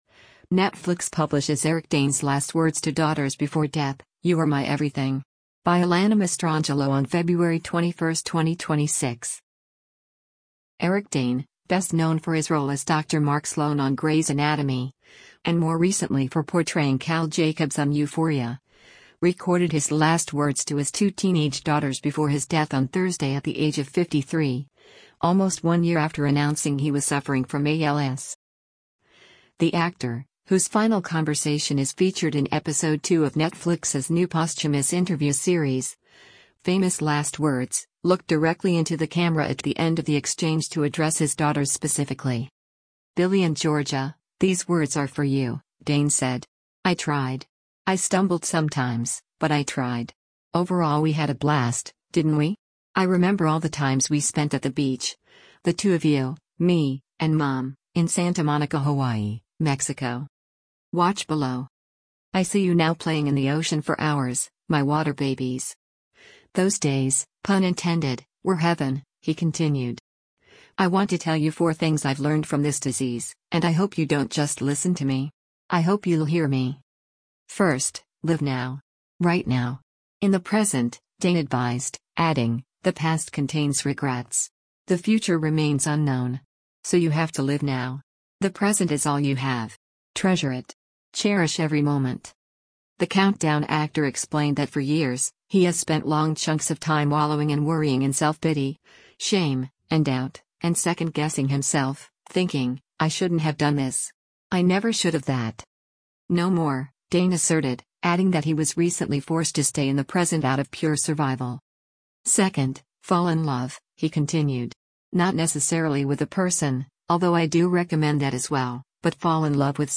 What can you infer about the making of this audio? The actor, whose final conversation is featured in episode two of Netflix’s new posthumous interview series, Famous Last Words, looked directly into the camera at the end of the exchange to address his daughters specifically.